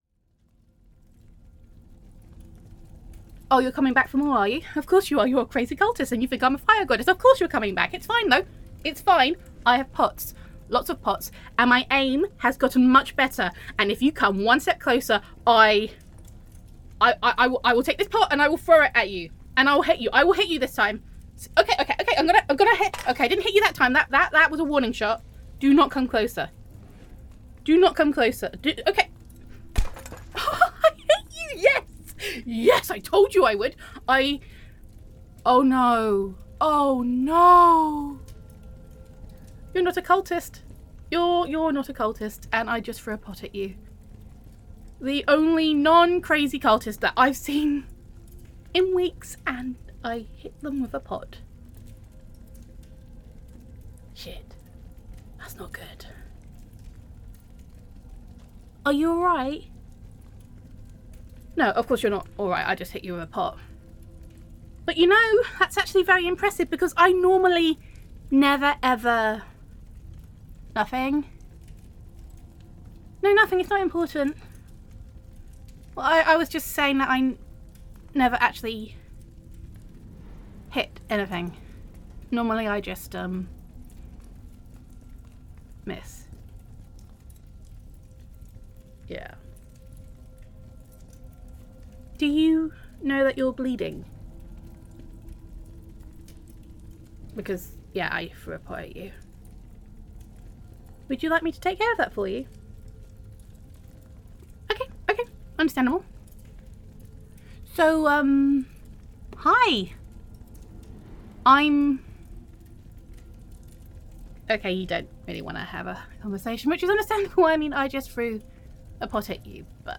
[F4A] Fifteen Minutes of Flame